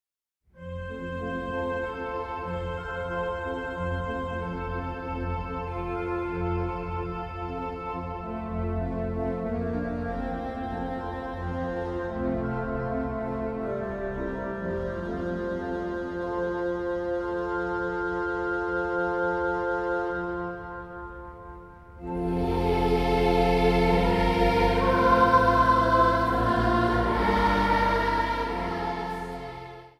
hobo
orgel.
Zang | Kinderkoor